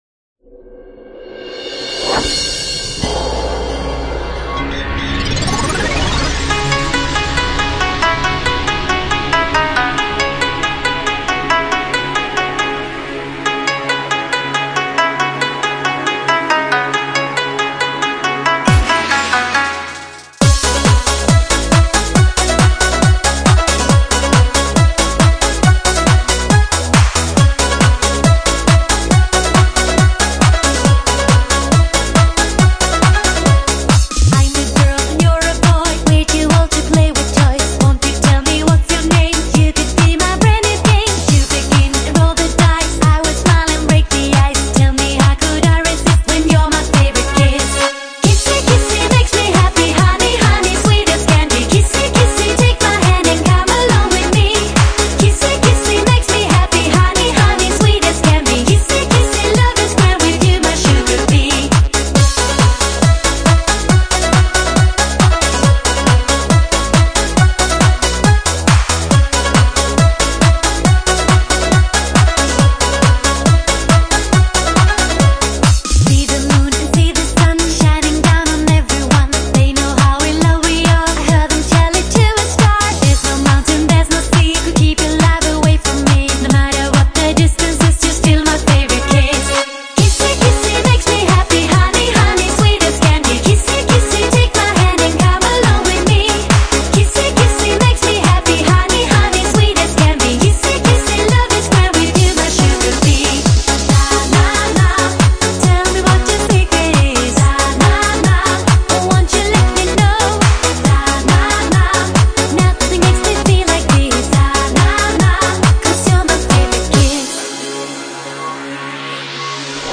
Genre: Electronic,Pop
Style: Euro-House,Eurodance,Europop,Bubblegum